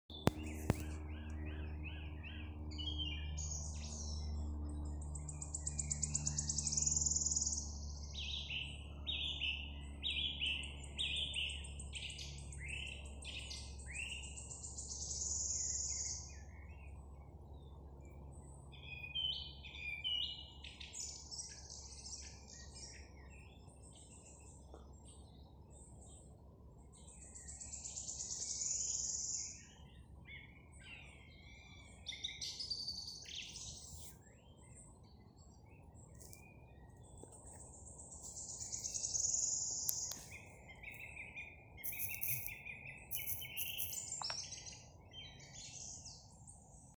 Dziedātājstrazds, Turdus philomelos
Ziņotāja saglabāts vietas nosaukumsMeža kapi
StatussDzied ligzdošanai piemērotā biotopā (D)
PiezīmesFonā dzied arī svirlītis